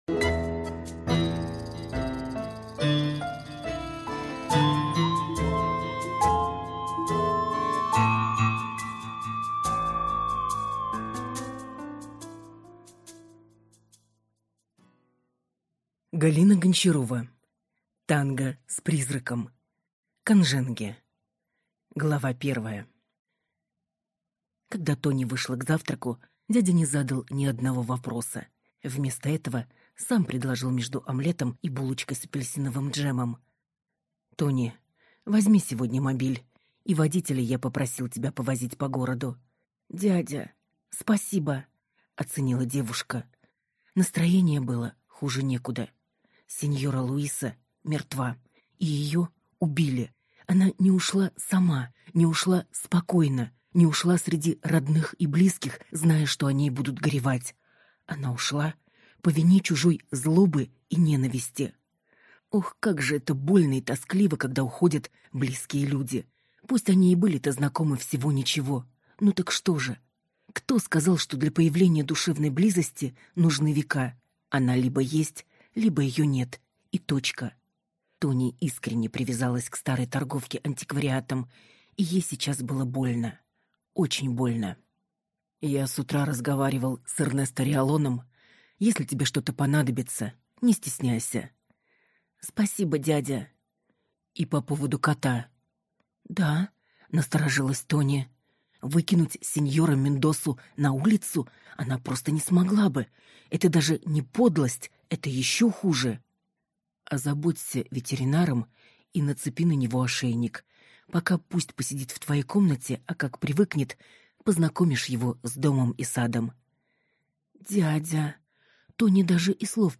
Аудиокнига Танго с призраком. Канженге | Библиотека аудиокниг